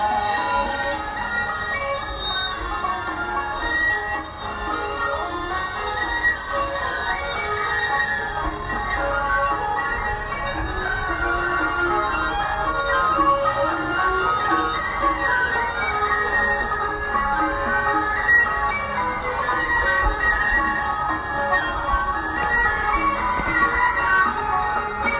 I’m not sure, but loud music has been playing over the loudspeaker since dawn. Mostly the same music, just in a loop.  It can be heard from 200+ meters away.
Here is a recording from our roof.  It sounds almost as loud in the bedrooms.